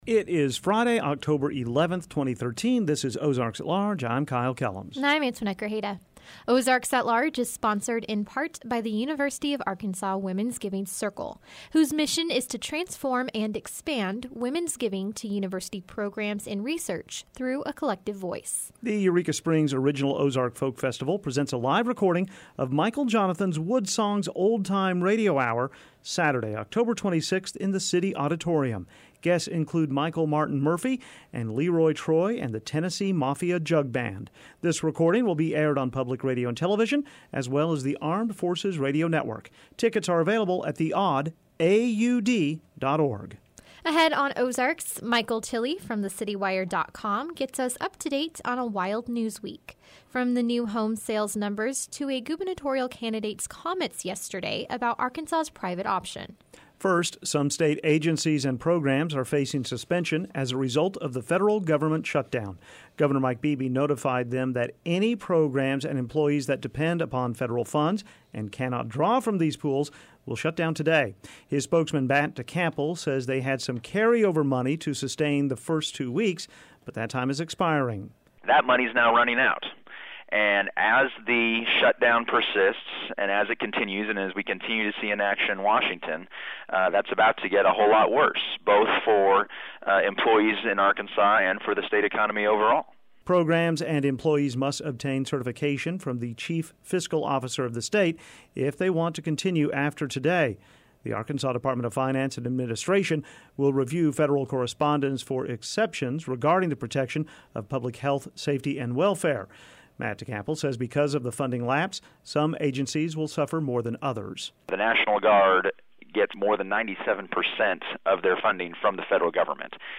Plus, Rosco Bandana joins us in the studio, and we travel to Dardanelle to see the home of Tusk, the live mascot of the Arkansas Razorbacks.